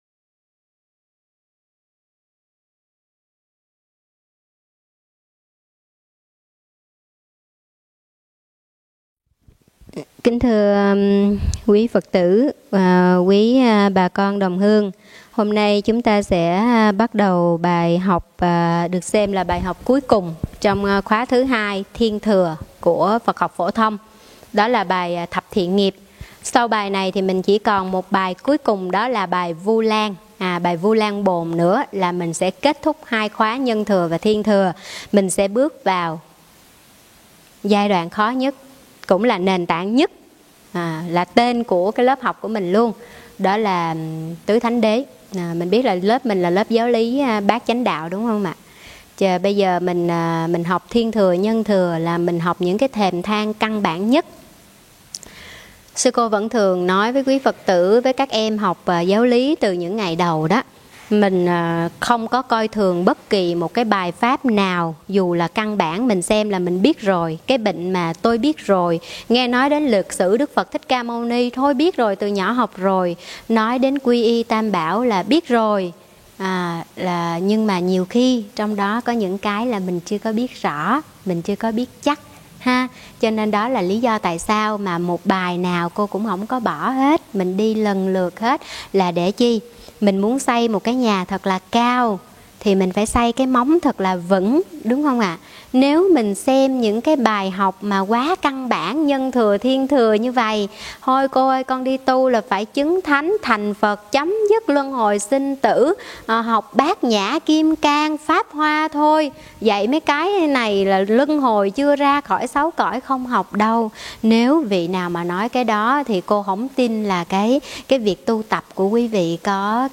Nghe mp3 thuyết pháp Thập thiện nghiệp 1